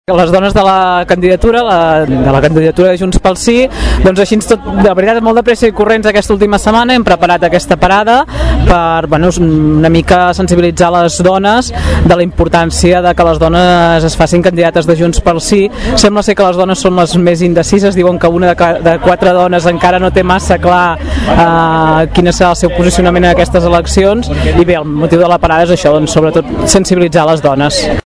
La regidora d’ERC + Gent de Tordera, Anna Serra, explicava en declaracions en aquesta emissora que les dones són més indecises que els homes, i que per això havien apostat per fer una crida al nostre municipi.